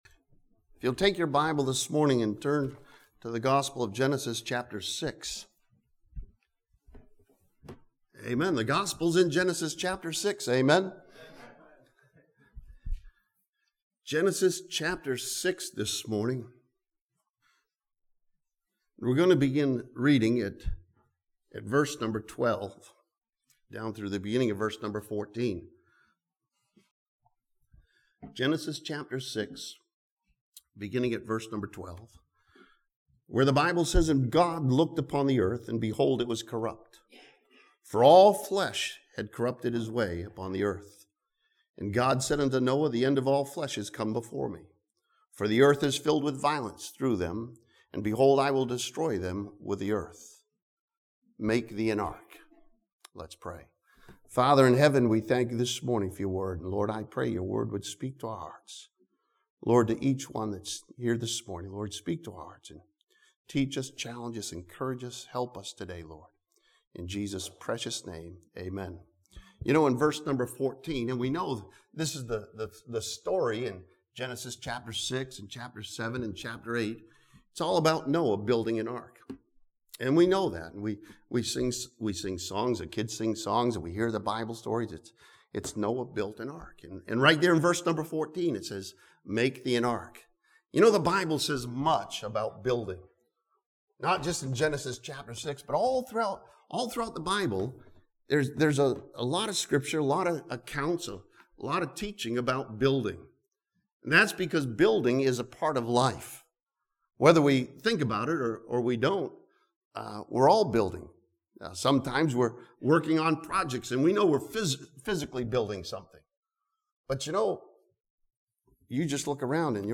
This sermon from Genesis chapter 6 challenges us to start building for the Lord following Noah's example.